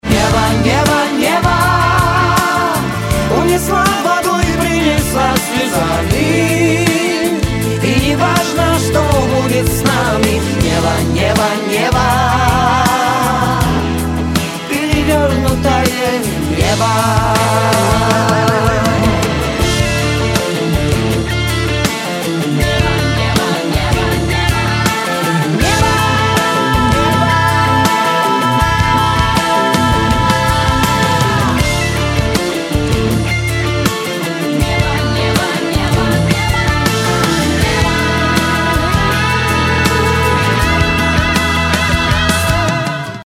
Романтические рингтоны
Рэп рингтоны